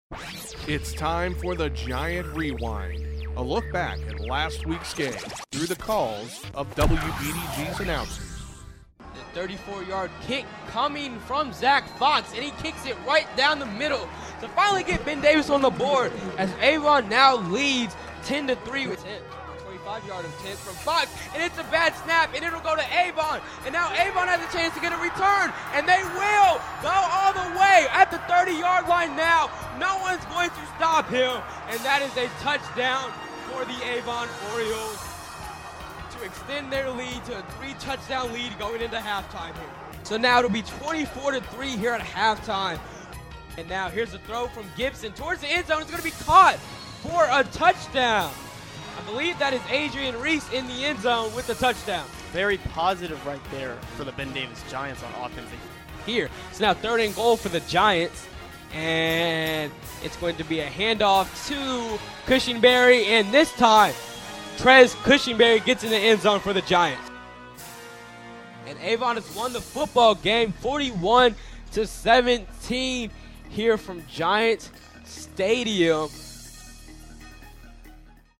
A look back at BD's 41-17 loss to Avon through the calls of WBDG's announcers.